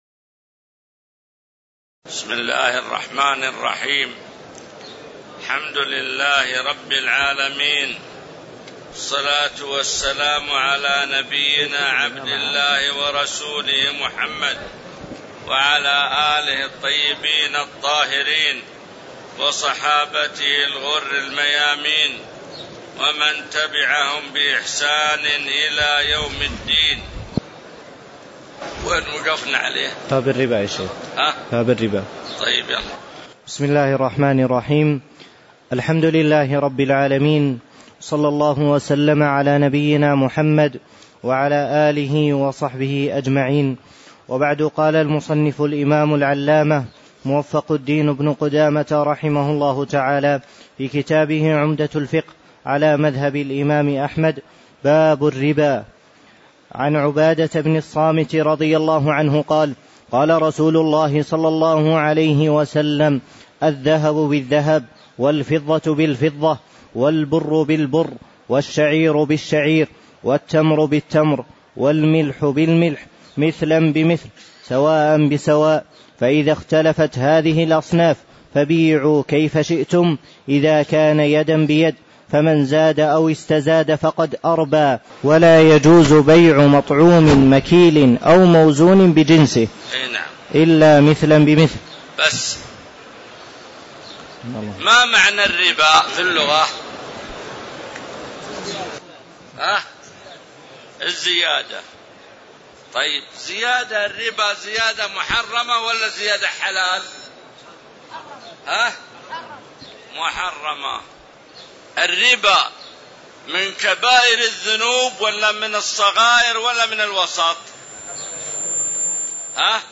تاريخ النشر ٢٦ رجب ١٤٣٧ المكان: المسجد النبوي الشيخ: معالي الشيخ د. عبدالله بن محمد المطلق معالي الشيخ د. عبدالله بن محمد المطلق باب الربا وبيع الأصول والثمار